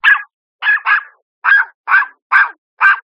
わんわわんわんわん。
小さな犬が怒っているのかな？